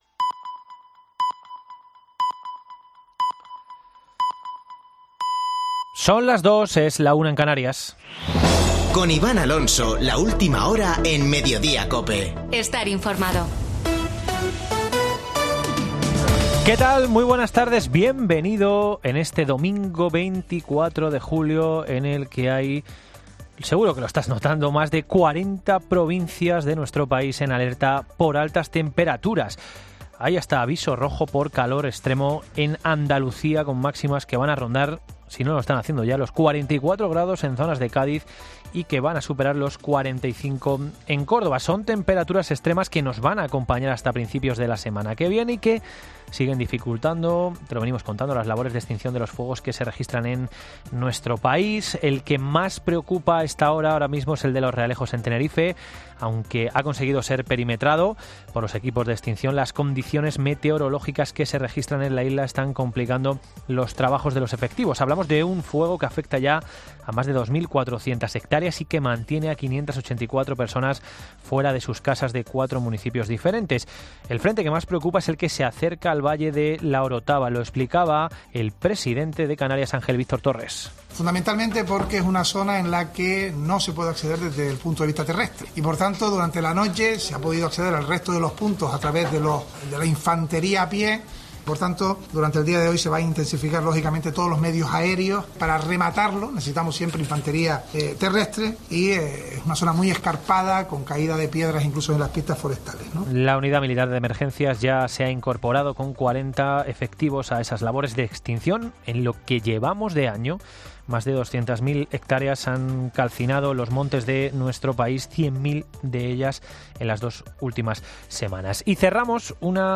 Boletín de noticias de COPE del 24 de julio de 2022 a las 14:00 horas